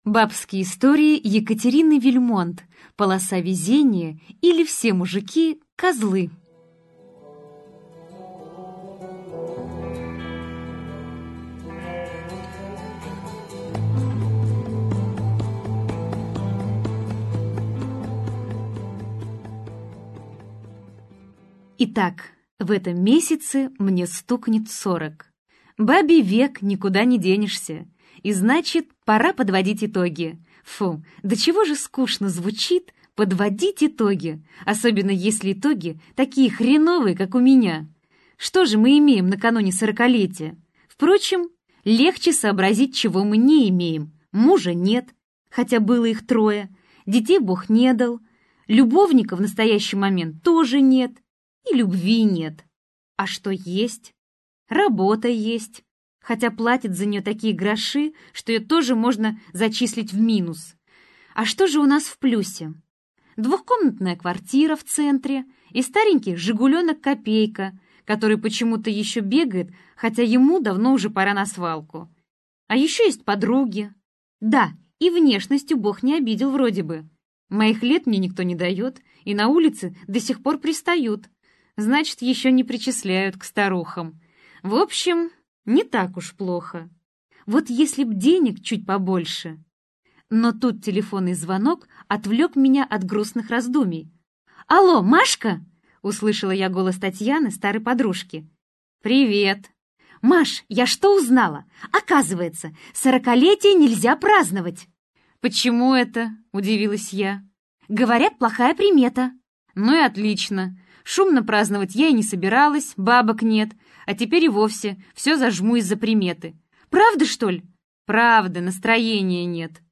Аудиокнига Полоса везения, или Все мужики козлы - купить, скачать и слушать онлайн | КнигоПоиск